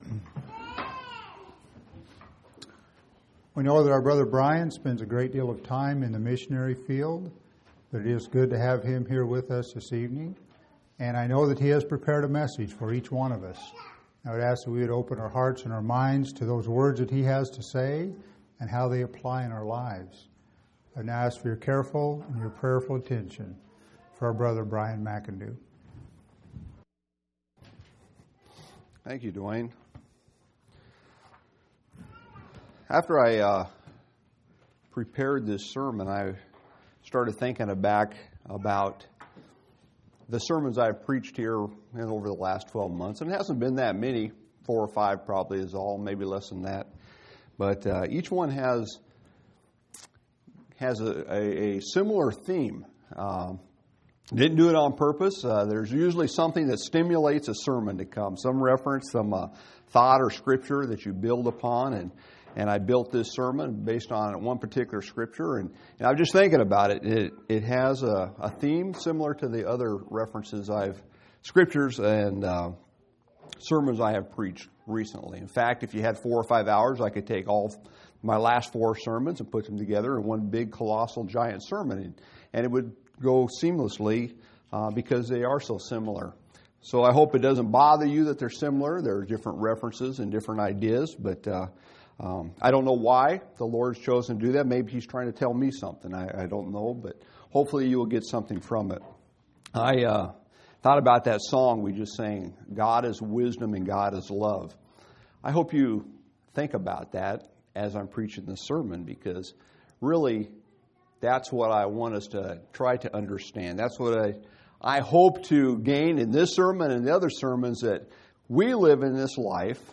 7/24/2005 Location: Phoenix Local Event